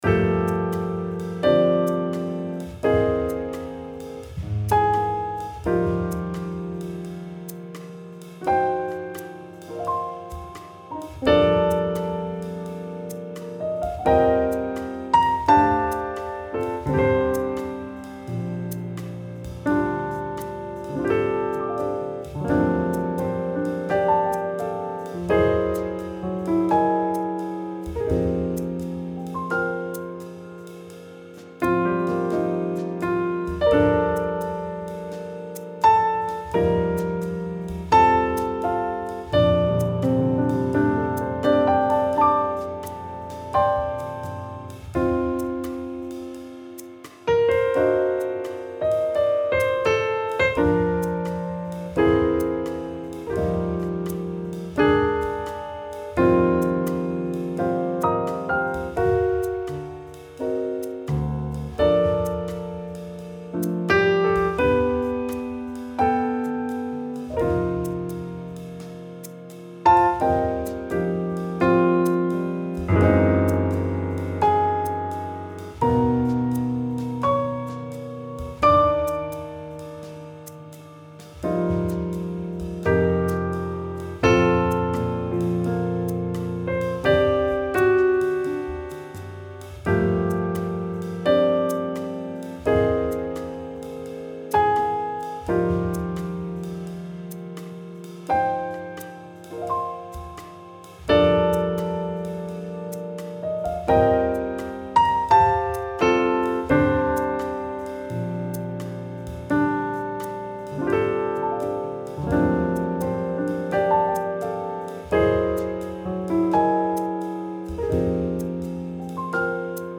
無料で使えるジャズ（JAZZ）のインストゥルメンタル楽曲フリーBGMがダウンロードできます。
ジャズ用の音源でオーソドックスな曲調のデータを使用してソフト音源で編集、制作しています。
JAZZ Piano
ジャズ・ピアノ